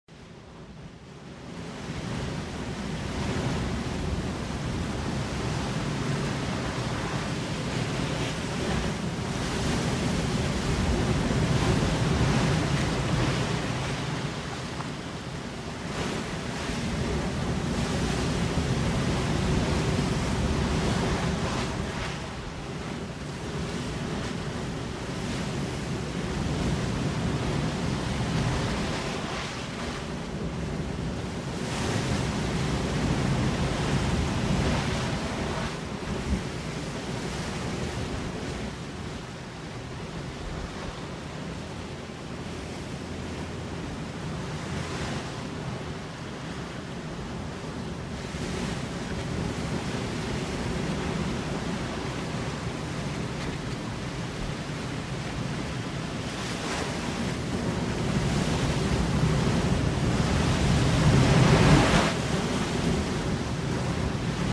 AIRE
Tonos EFECTO DE SONIDO DE AMBIENTE de AIRE
aire.mp3